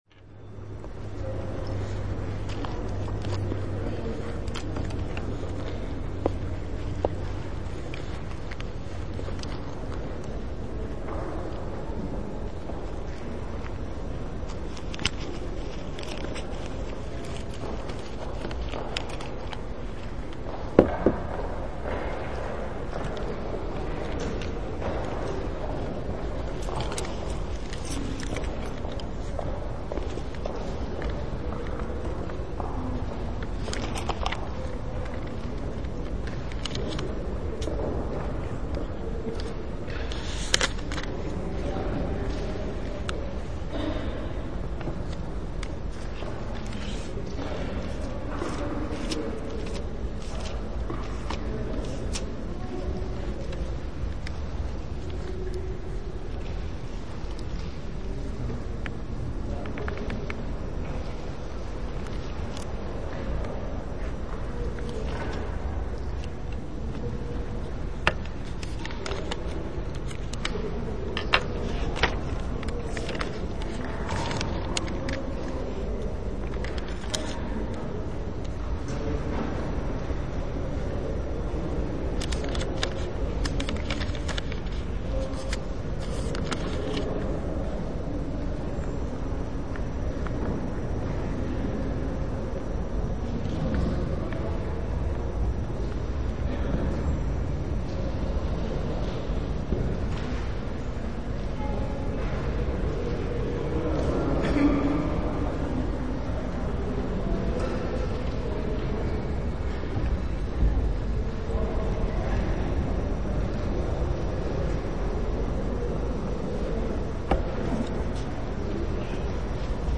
La Biblioteca Mexico (estación Balderas de la línea 1 del metro, ciudad de Mexico) fue la primera biblioteca de mi vida, estudiaba la secundaria y atravesar gran parte de la ciudad para llegar a ella era ya una aventura.